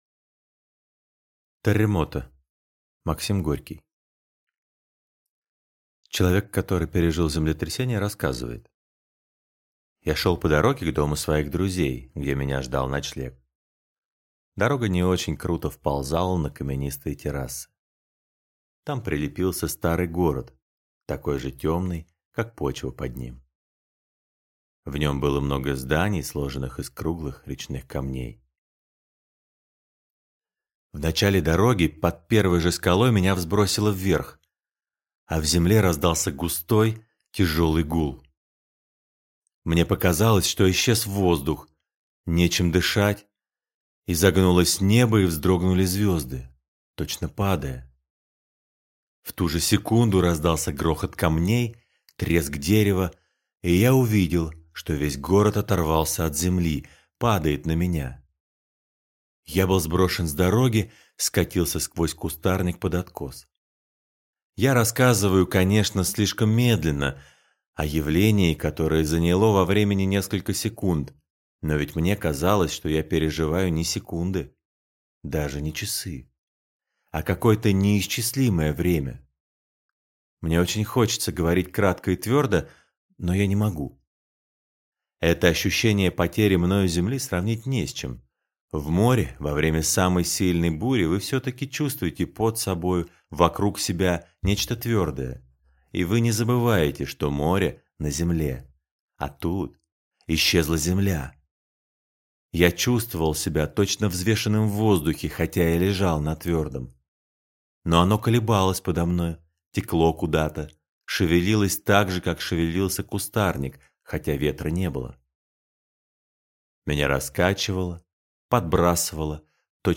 Аудиокнига Терремото | Библиотека аудиокниг